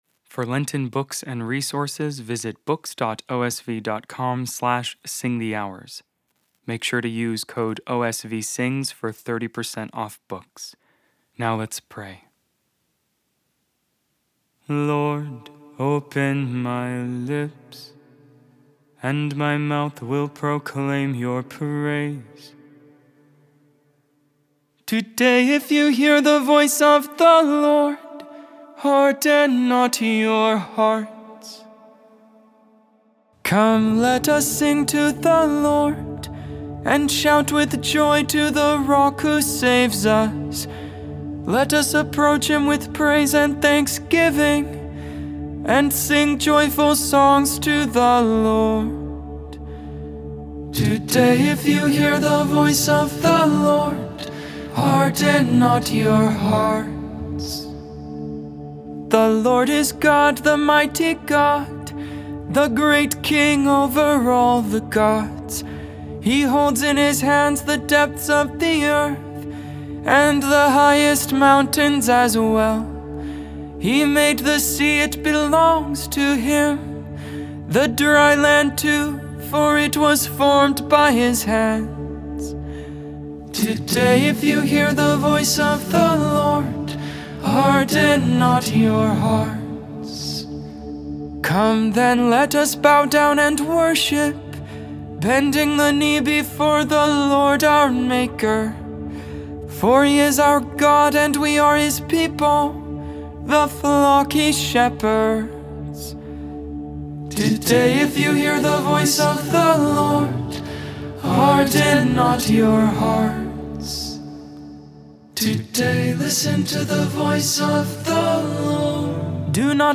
Psalm 51 (Tone 8)